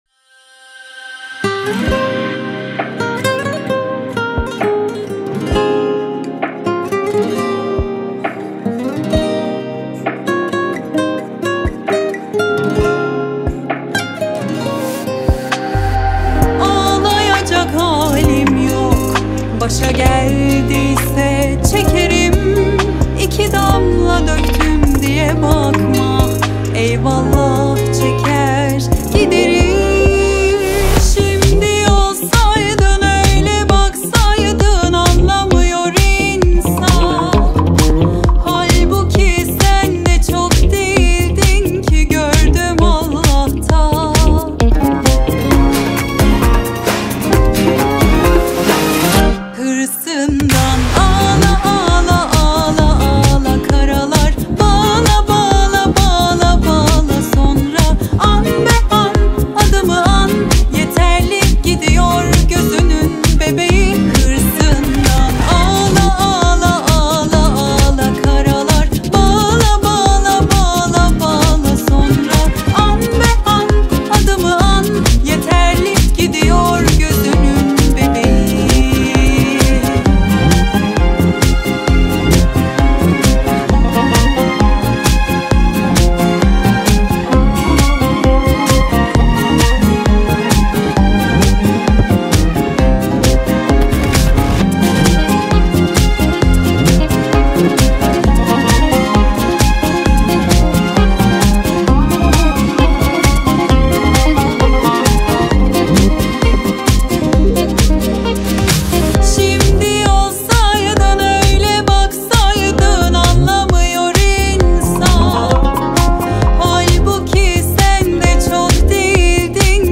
Турецкая песня